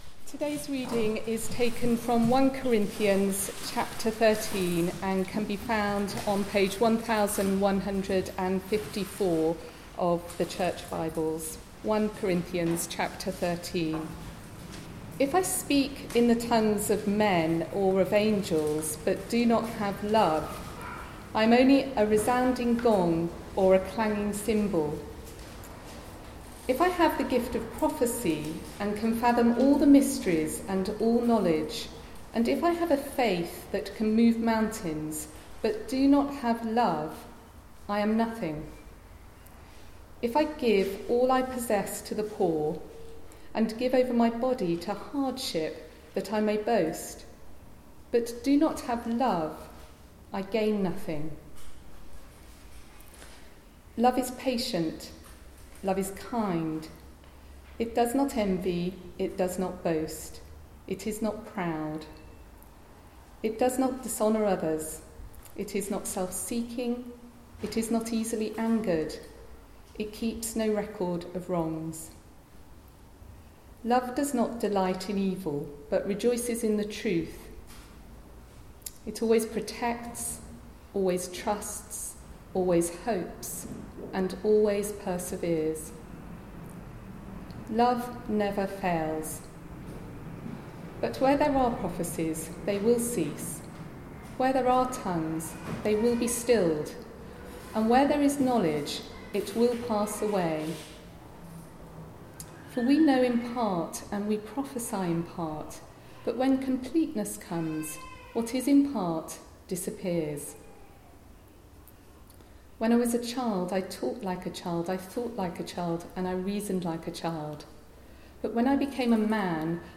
1 Corinthians 13 Service Type: Weekly Service at 4pm Bible Text